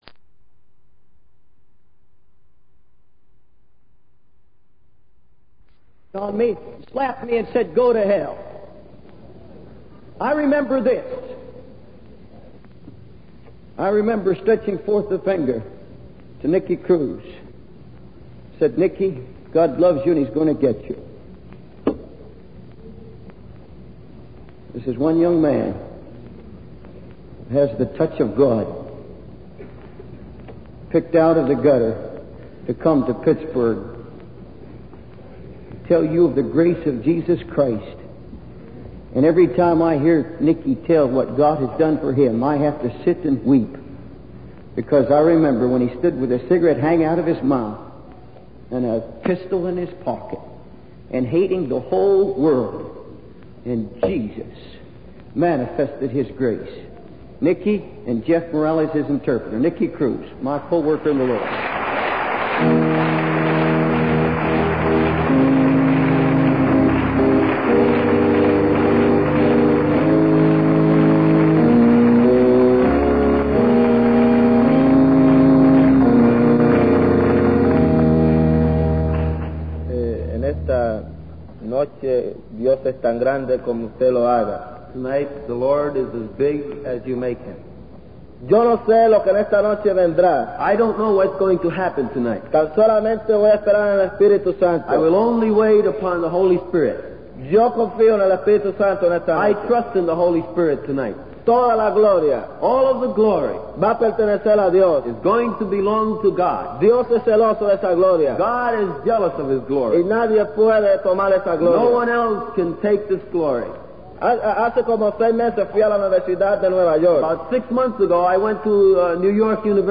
In this sermon, the speaker shares a personal story of his involvement in a violent gang. He describes being brutally attacked and seeking revenge with his own gang.